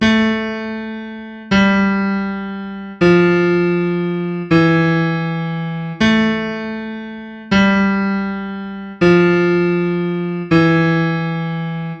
The Passacaglia
In general the Passacaglia refers to a slow piece with a repeating bass pattern and harmony belonging to it.
We are going to use the pattern shown below (A, G, F, E).